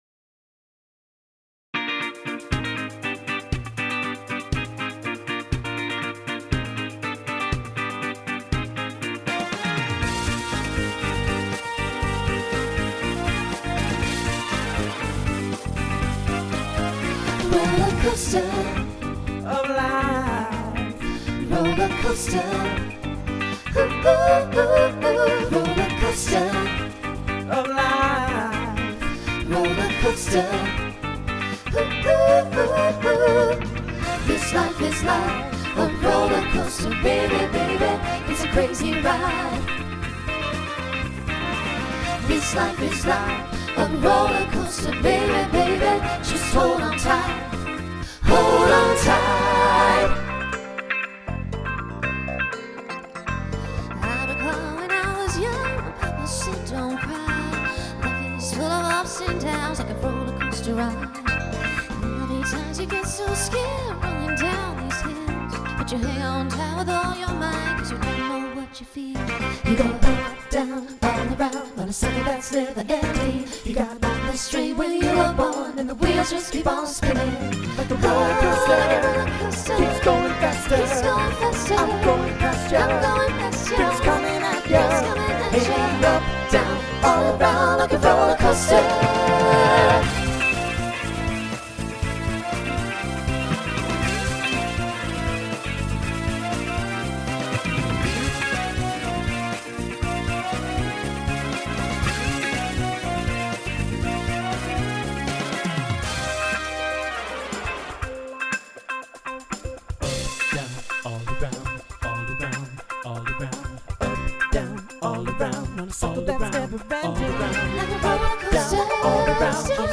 Voicing SATB Instrumental combo Genre Disco , Rock